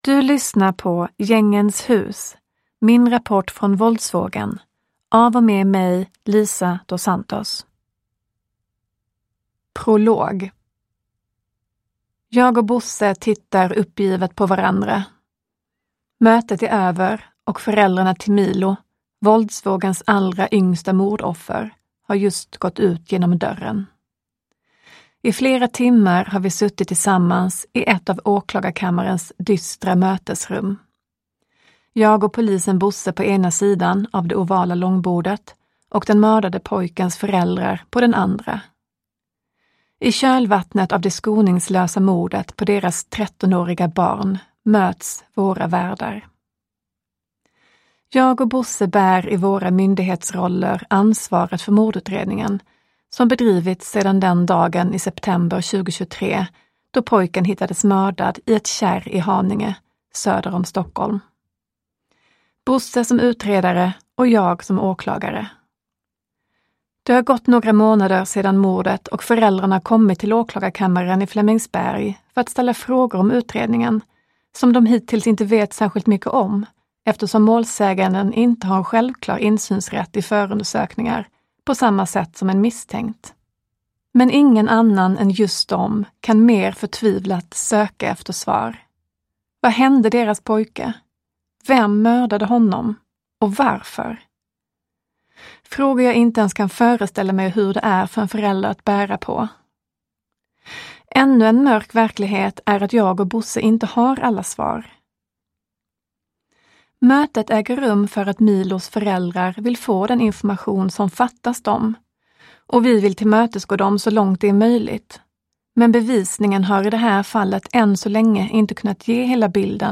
Gängens hus : min rapport från våldsvågen – Ljudbok